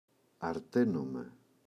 αρταίνομαι [a’rtenome] – ΔΠΗ